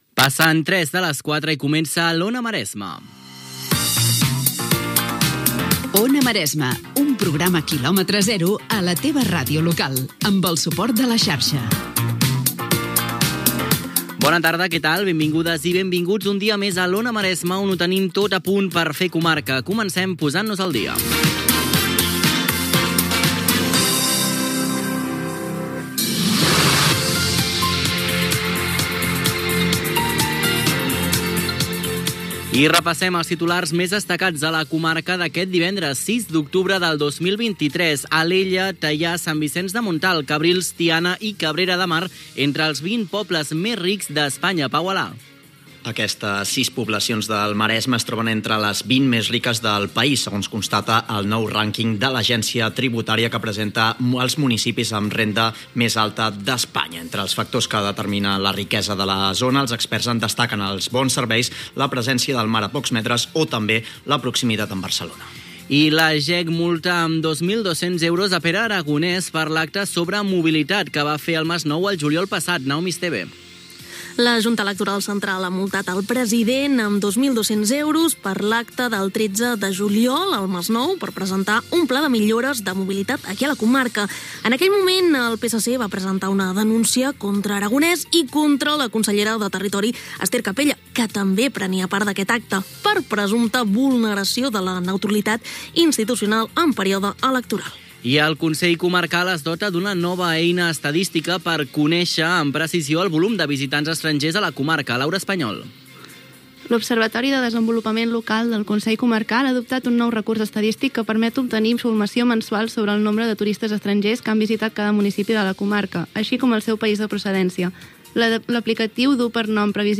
Hora, indicatiu del programa, presentació, data, sumari informatiu del Maresme, indicatiu del programa, hora, informació sobre els municipis amb alt nivell de vida del Maresme
Info-entreteniment